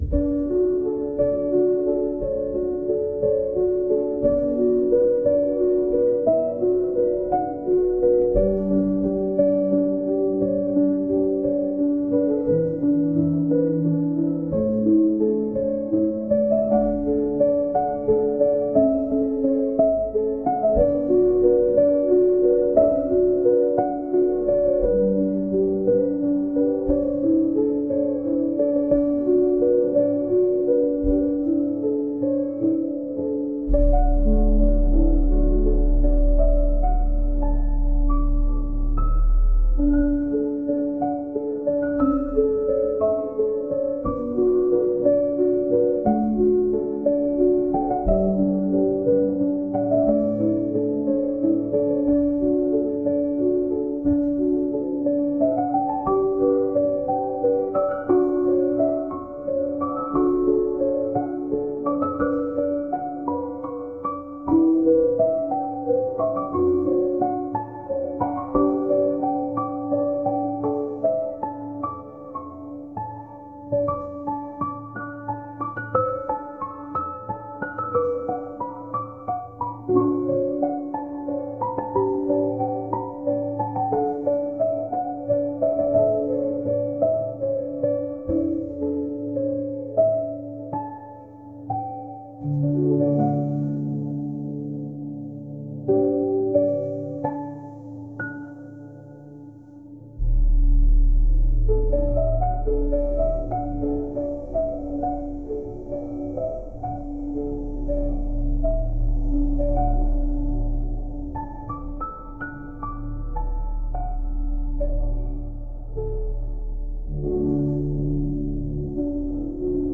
幻想的